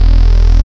72.01 BASS.wav